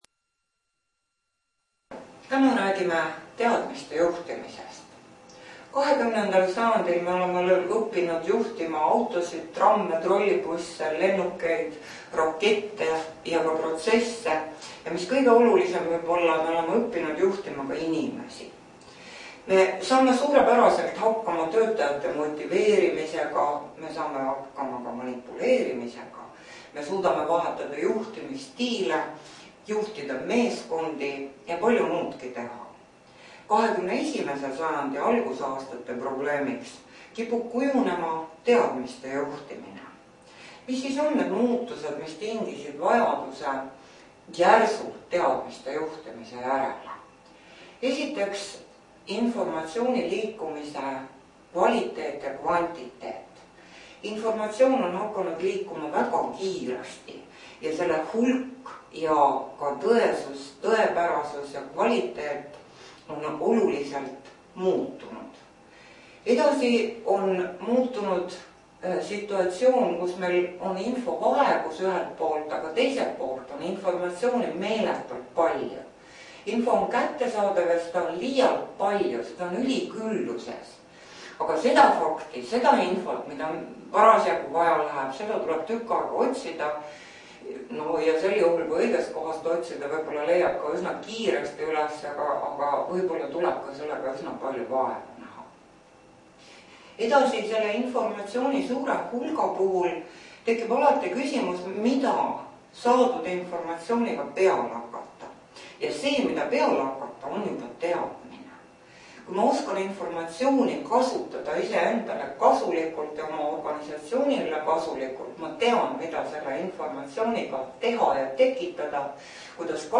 Teadmiste juhtimine loengu MAPP printimiseks Mapi lugemiseks vajate programmi Adobe Acrobat Reader kui teil seda arvutis ei ole siis leiate selle siit Teadmiste juhtimine loeng MP3 failina (19MB)